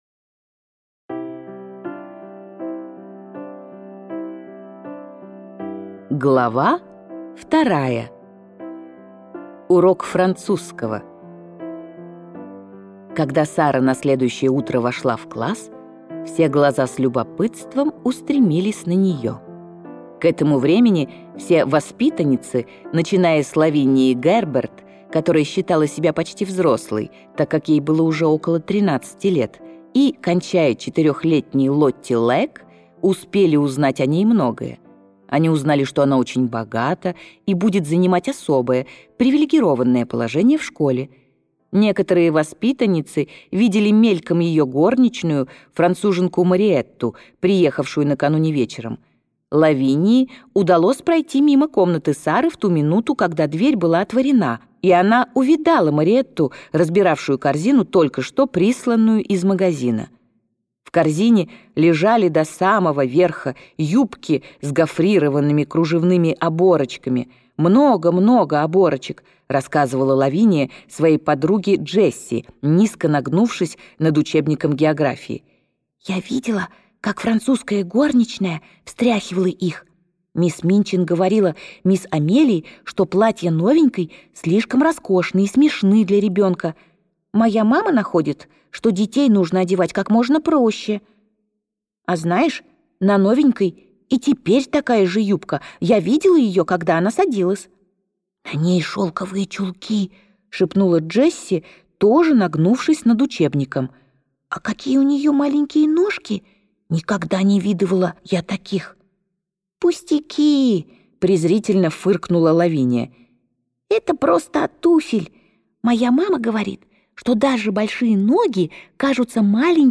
Аудиокнига Маленькая принцесса | Библиотека аудиокниг